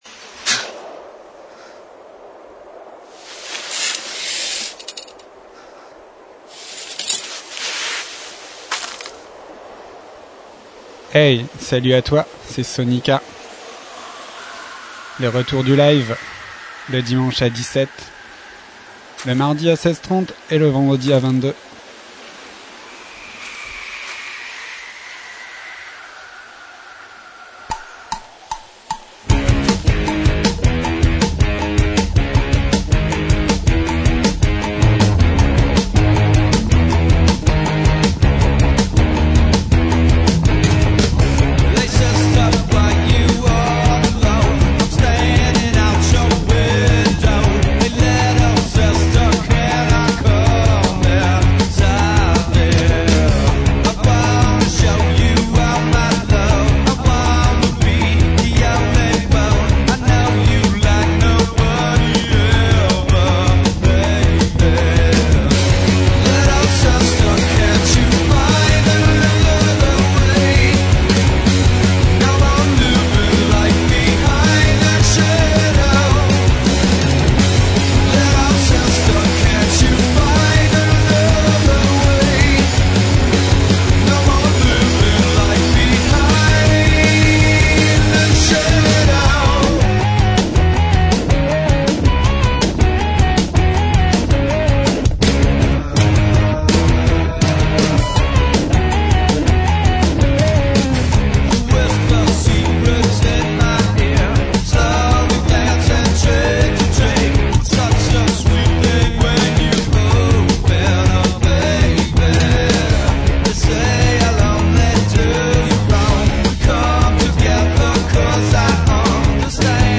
SoniKa de retour en live !!